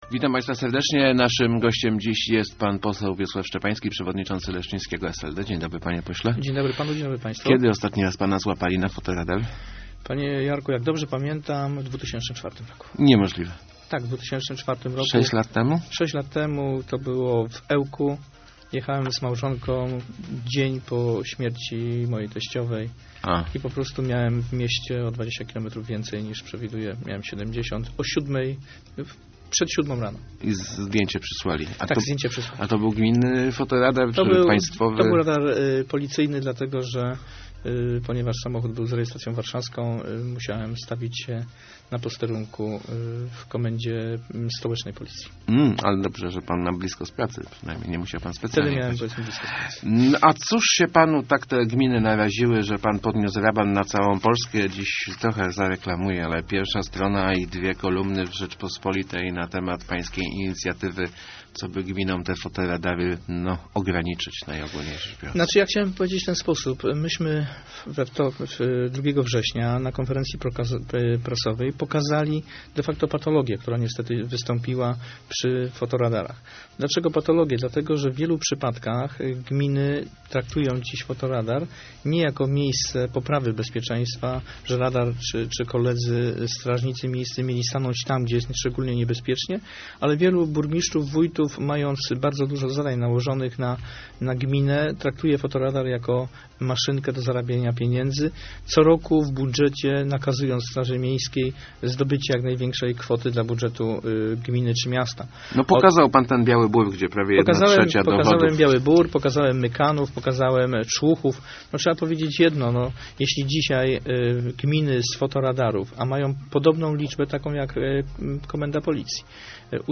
Jeżeli już gminy chcą zarabiać fotoradarami, niech przeznaczają te pieniądze na poprawę bezpieczeństwa na drogach - mówił w Rozmowach Elki poseł Wiesław Szczepański, jeden z autorów zmian w prawie, mających uzdrowić zasady korzystania z tych urządzeń przez samorządy.